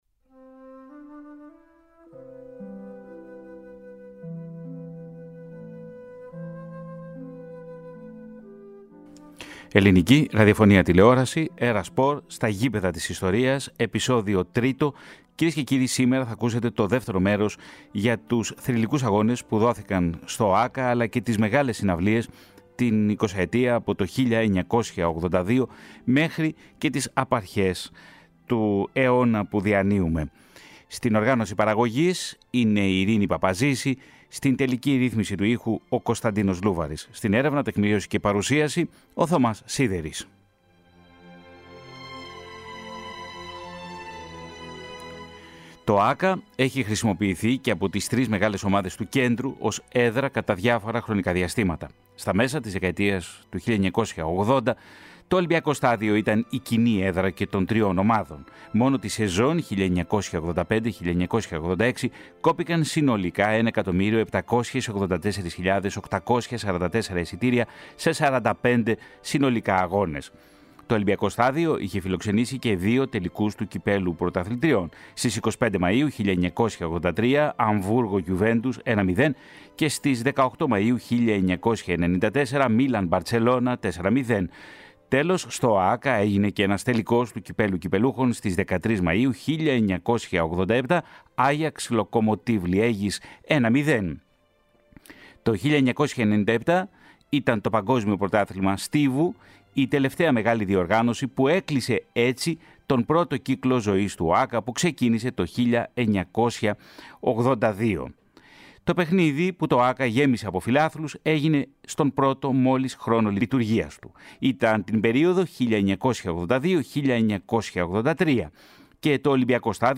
To ραδιοφωνικό ντοκιμαντέρ θα μεταδοθεί σε δύο μέρη, σε πρώτο πλάνο το Ολυμπιακό Στάδιο και τα σημαντικότερα αθλητικά γεγονότα σε μία περίοδο 22 χρόνων, στο φόντο η κοινωνική πραγματικότητα των δεκαετιών 1980 και 1990.